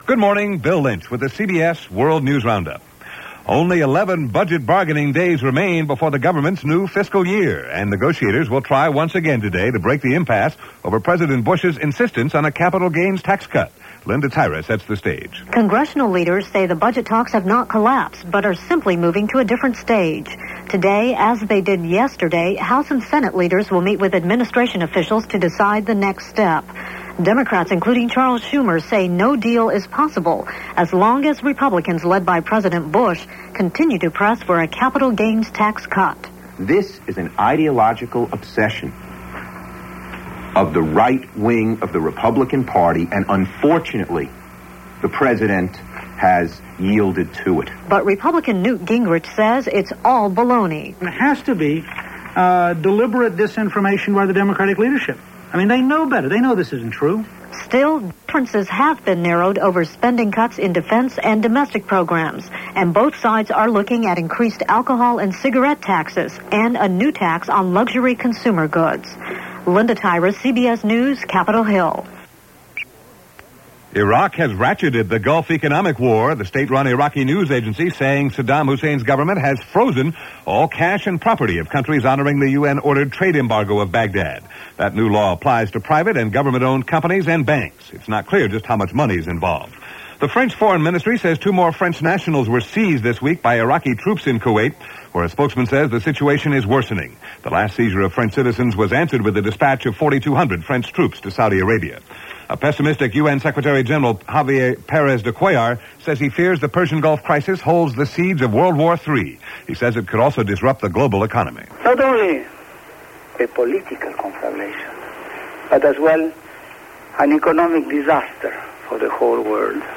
And that’s a very small slice of what went on, this September 19th in 1990 as reported by The CBS World News Roundup.